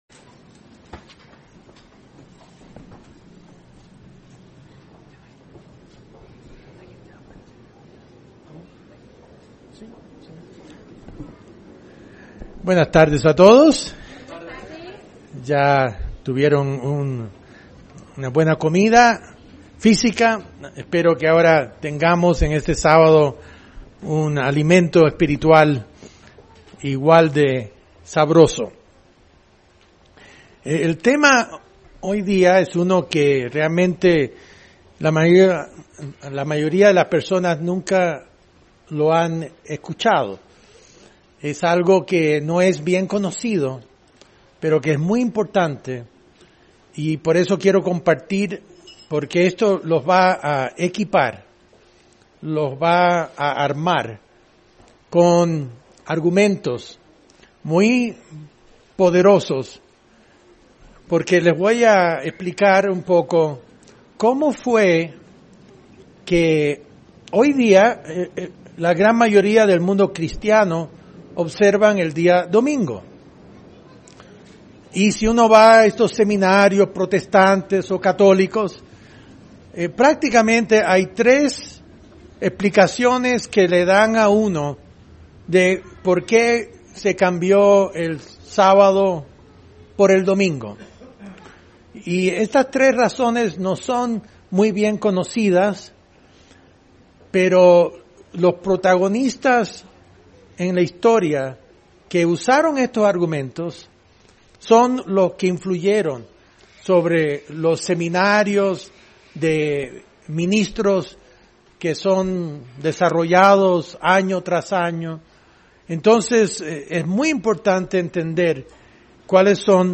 ¿De qué manera fue que el día de reposo se cambió de sábado a domingo? ¿Es esta una práctica acorde a las enseñanzas de Jesucristo? Mensaje entregado el 11 de agosto de 2018.